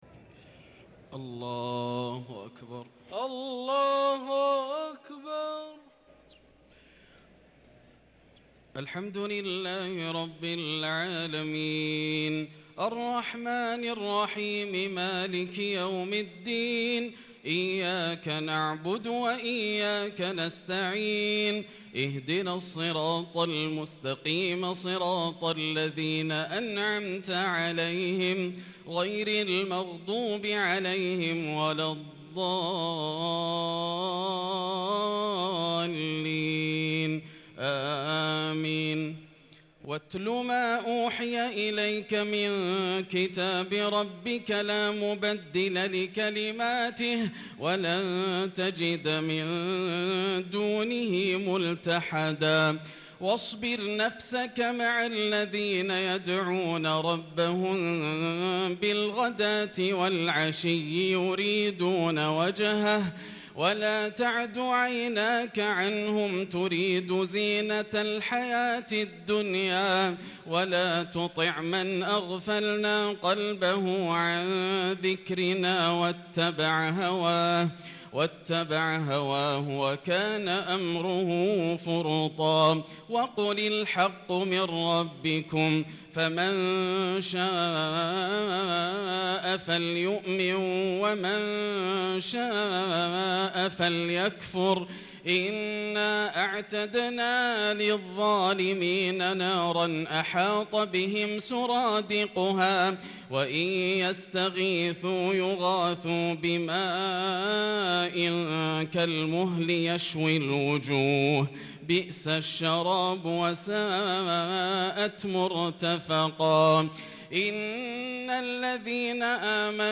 صلاة التراويح ليلة 20 رمضان 1443 للقارئ ياسر الدوسري - التسليمتان الأخيرتان صلاة التراويح